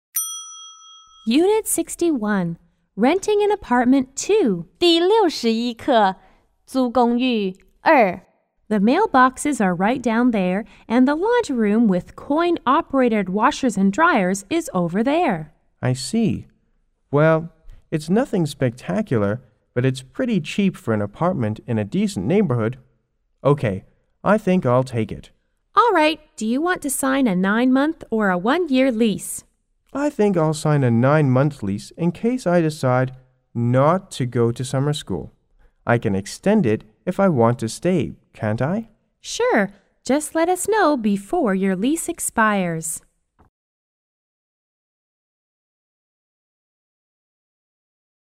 M= Manager R= Renter